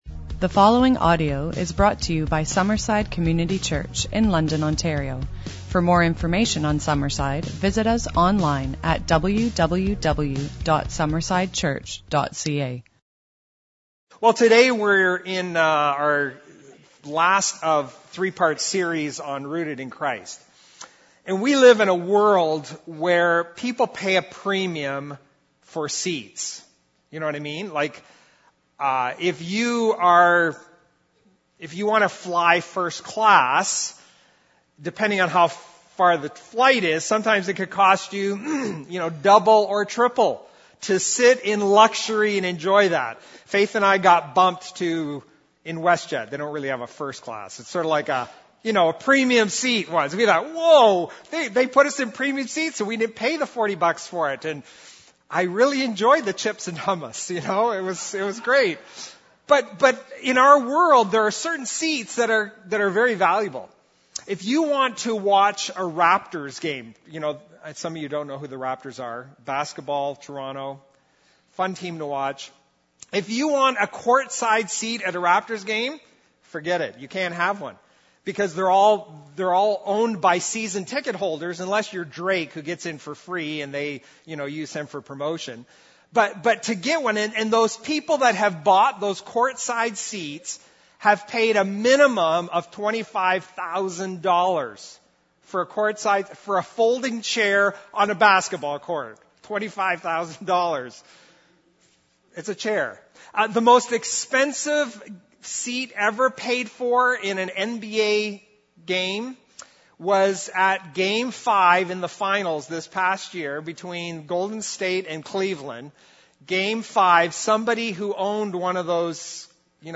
This message answers that important question as the conclusion to our series “Rooted in…